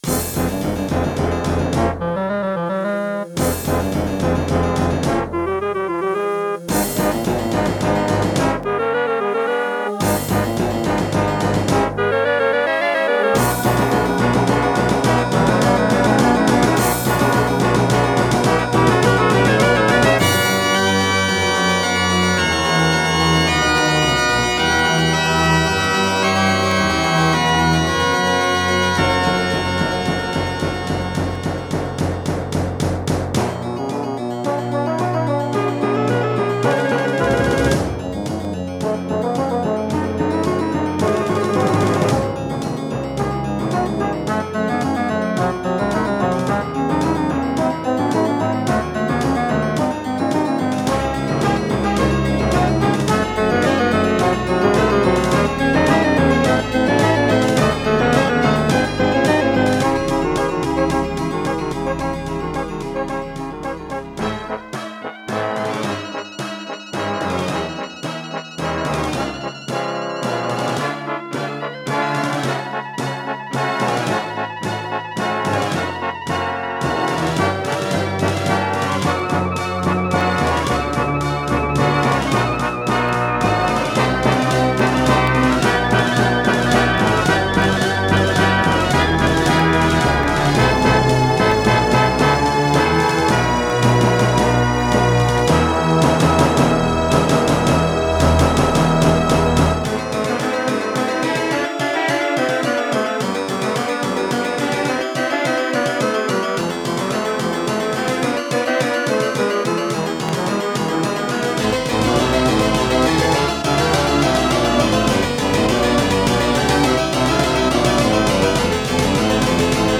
Terratec WaveSystem SIWT-1
* Some records contain clicks.